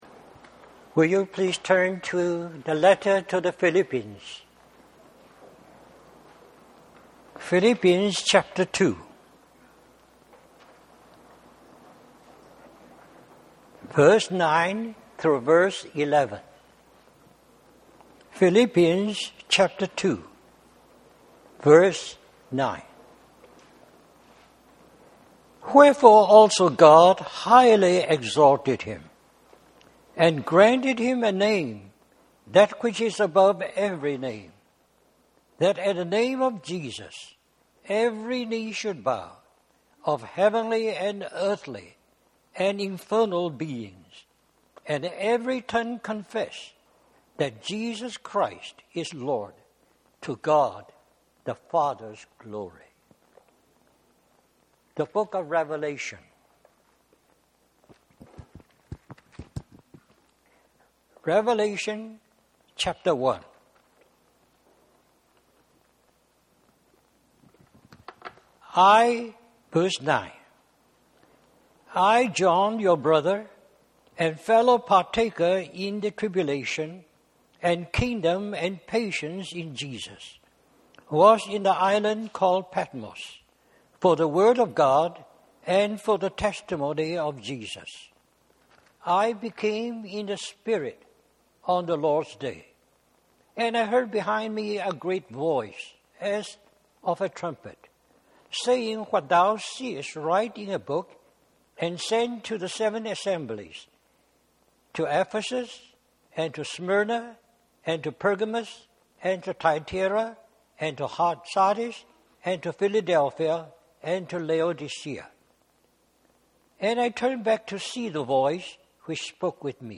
Christian Family Conference
Message